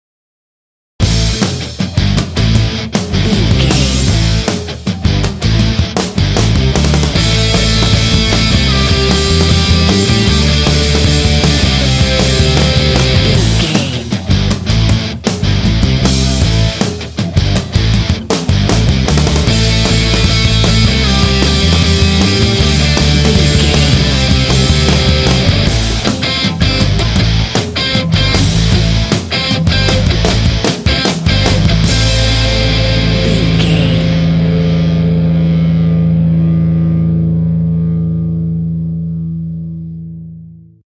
Epic / Action
Aeolian/Minor
driving
powerful
energetic
heavy
electric guitar
bass guitar
drums
rock
heavy metal
alternative rock
classic rock